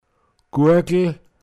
Wortlisten - Pinzgauer Mundart Lexikon